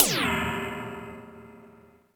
FX RIDE.wav